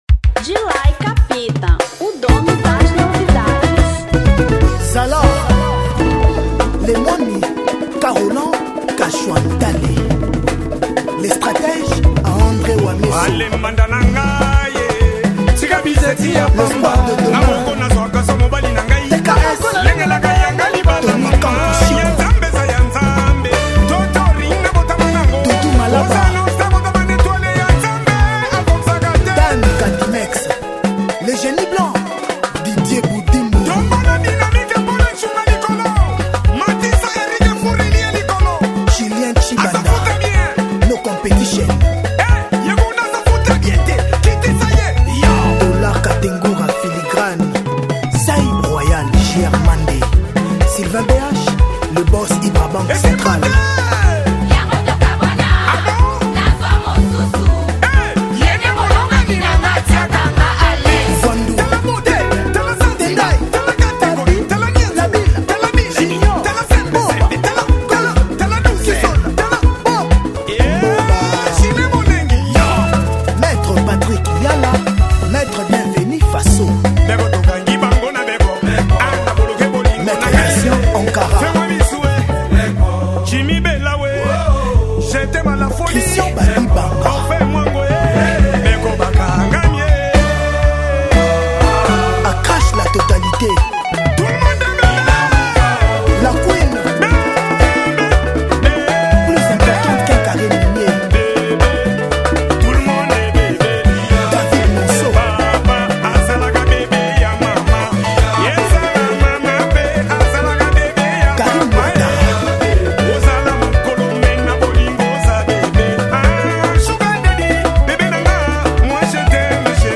Rumba 2025